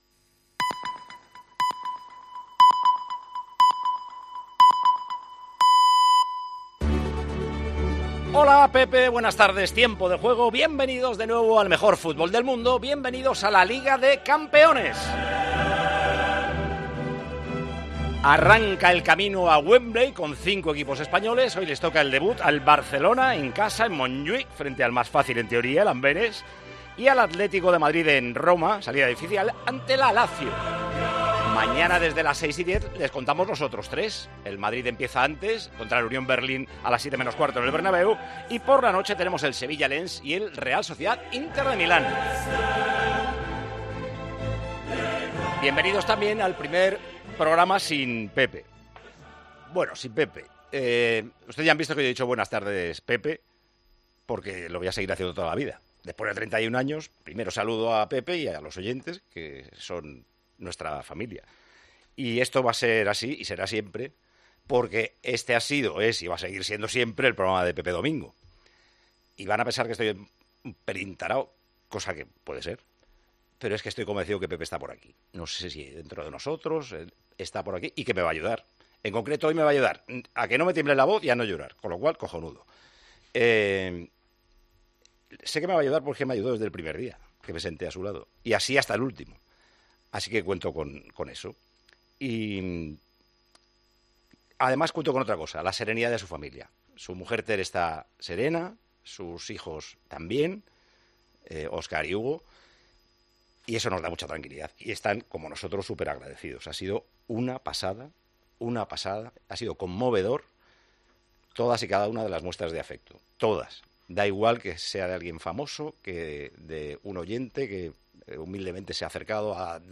El discurso completo de Paco González para Pepe Domingo Castaño